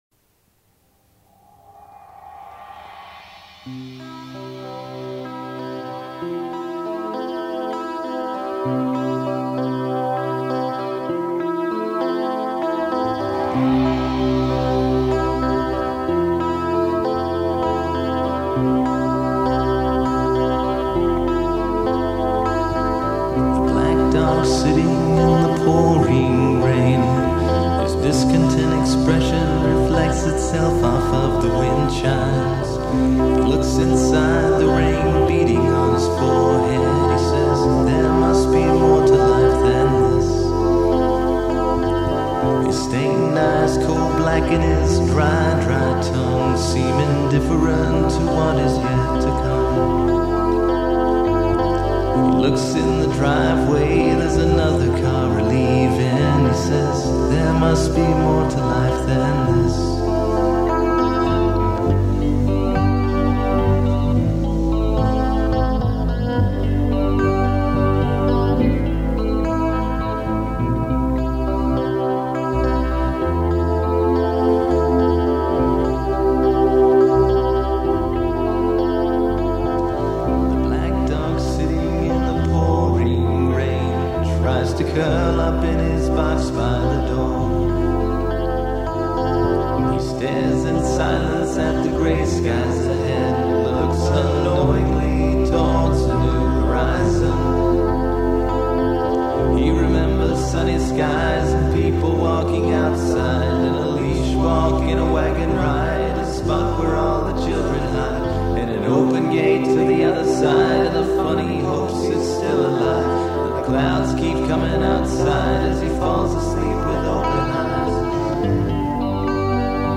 vocals, guitar
keyboard, vocals
bass
drums
guest keyboard on this track